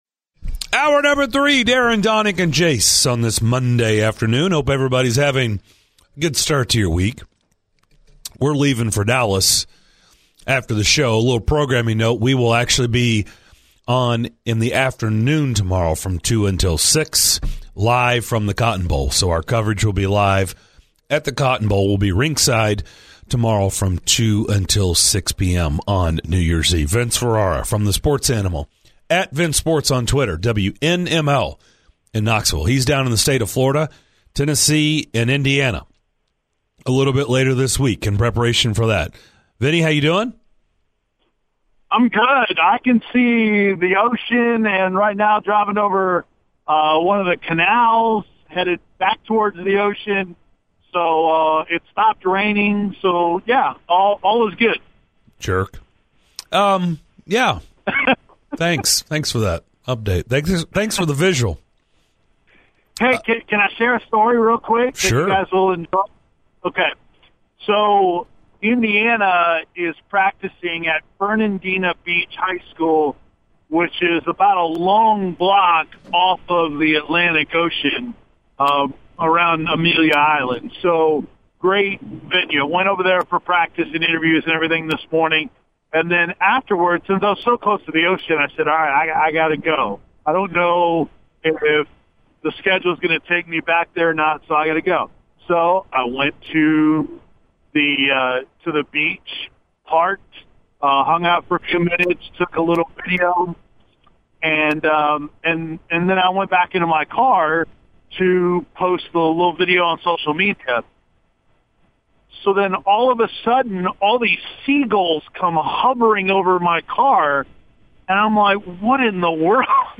the show's callers chime in during this week's edition of "This is what I know".